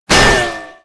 acannonimpactmetala01.wav